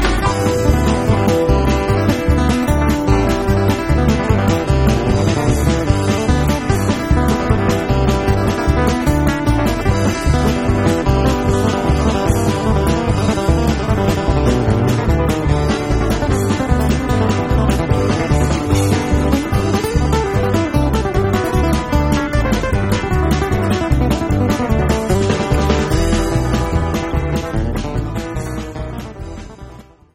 Acoustic
Americana
Bluegrass
Jamband